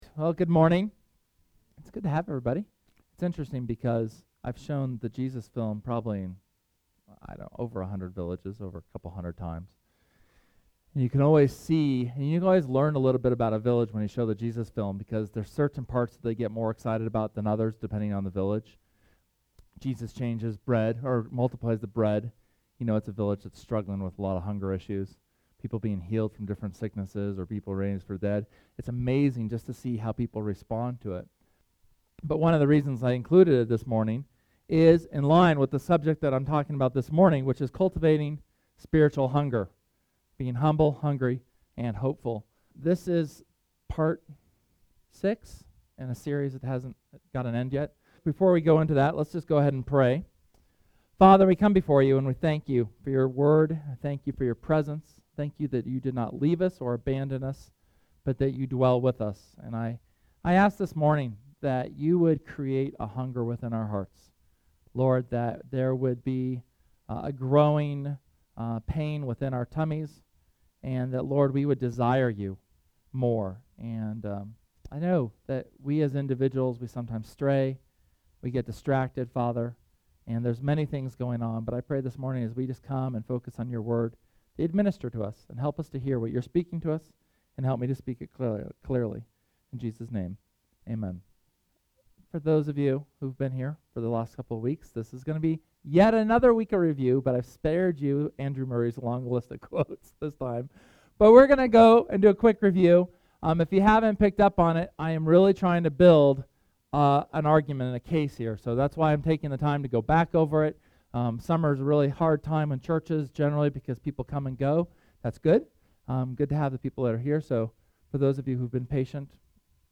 SERMON: How to cultivate spiritual hunger (HS #6)